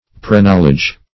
Preknowledge \Pre*knowl"edge\, n. Prior knowledge.